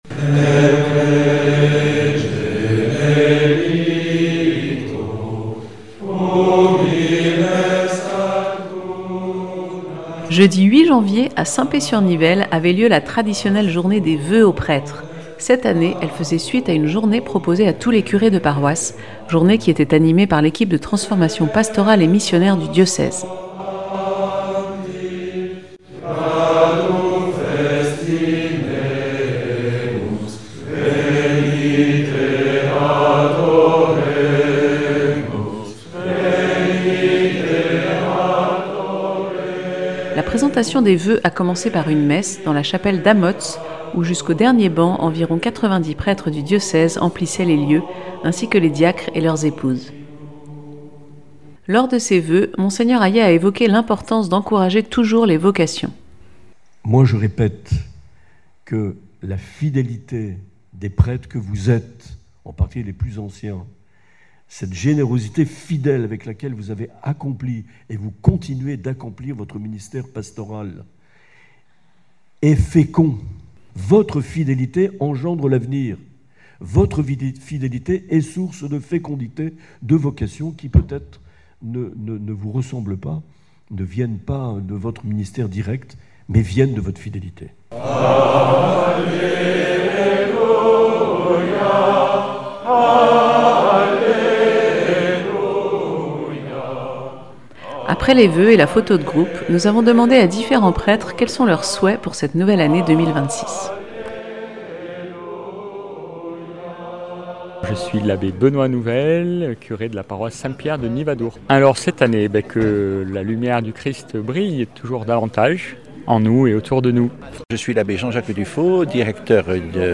Interviews et reportages du 20 janv.